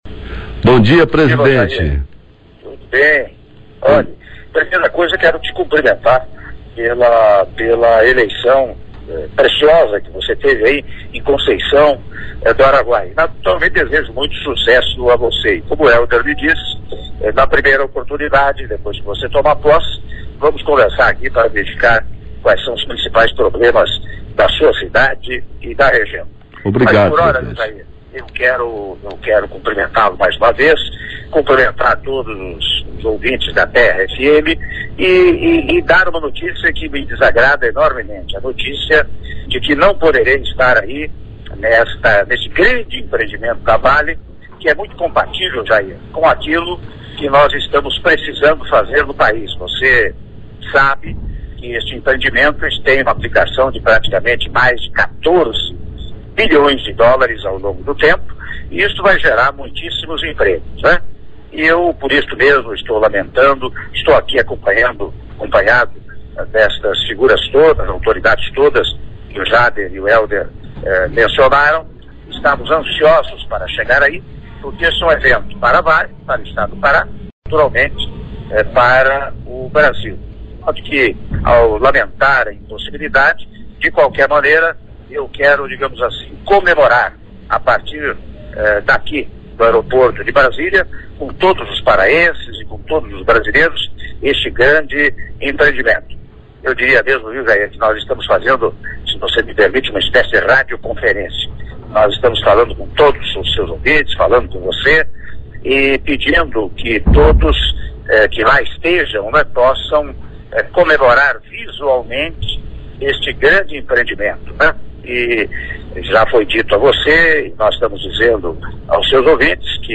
Áudio da entrevista concedida pelo Presidente da República, Michel Temer, à Rádio Terra FM do Pará (02min56s)